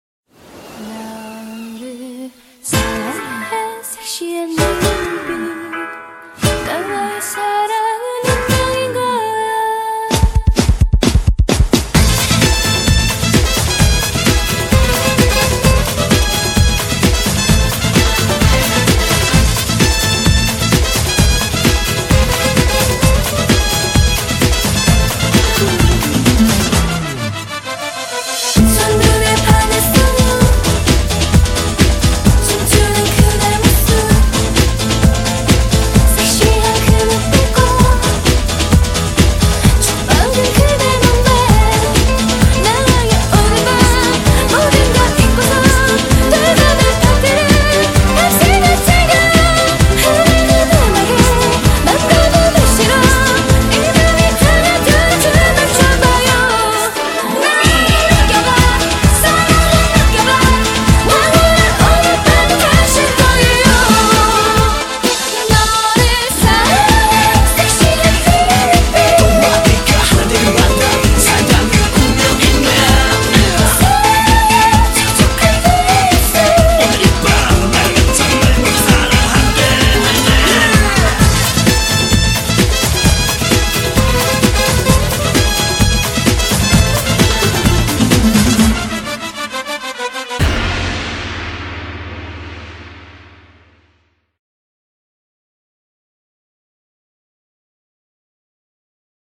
BPM108--1
Audio QualityPerfect (High Quality)